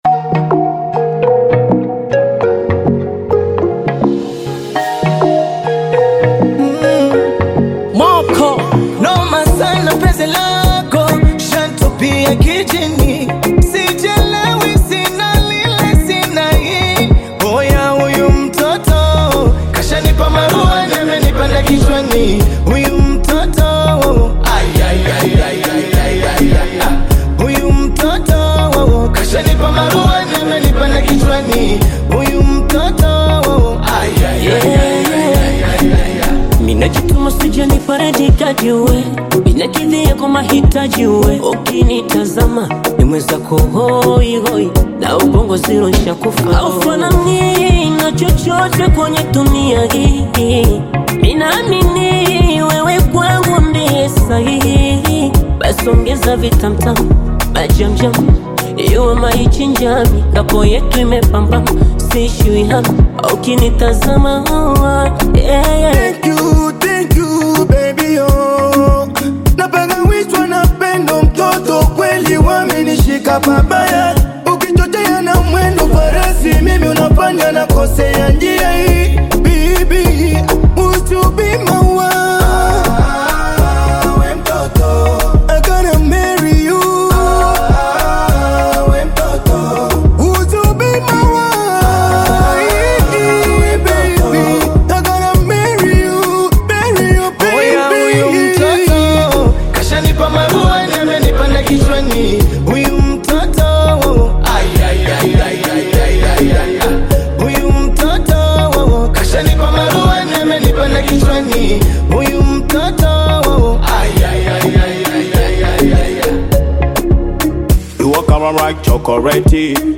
Tanzanian Bongo Flava group